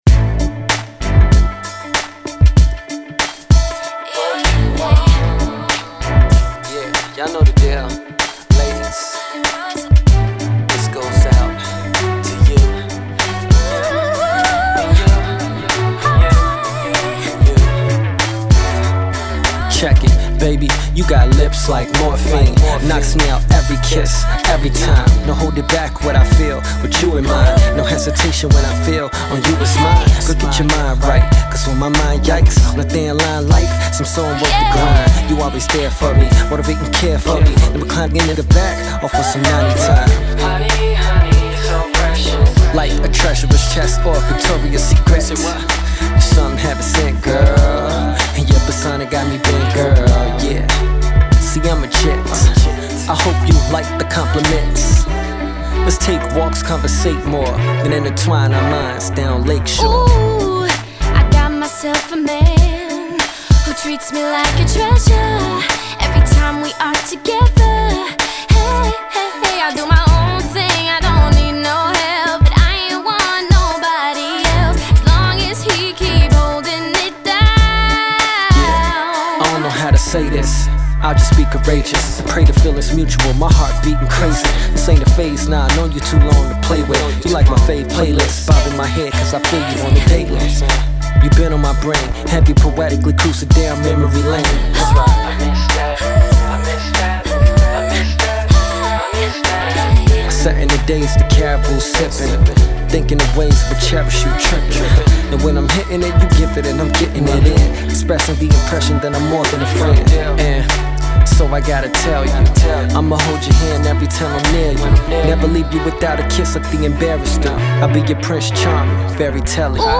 Hiphop
lays his smooth hip hop vocals on this track.